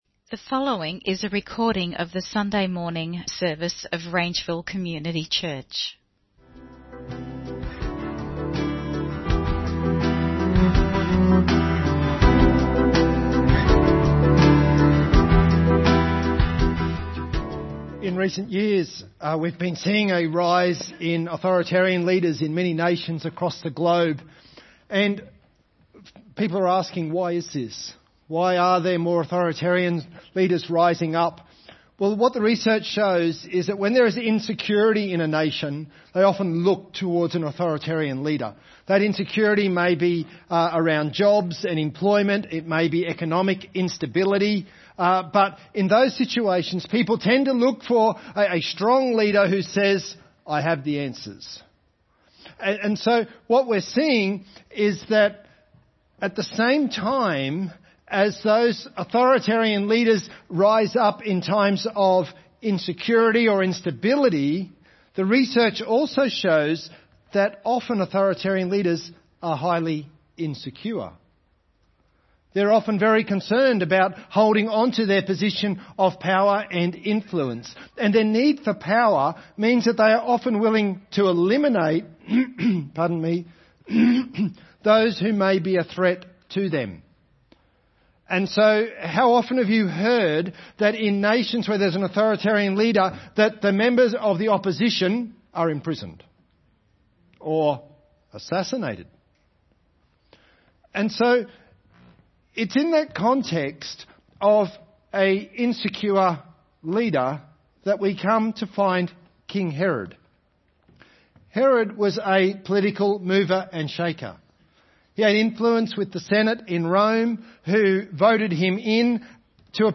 The Christmas Gift for the Magi (Sermon Only - Video + Audio)